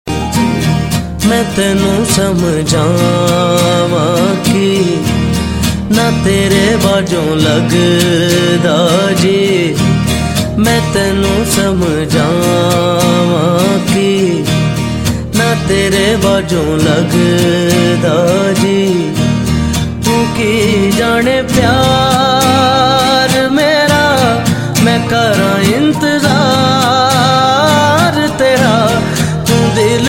Daily New Ringtones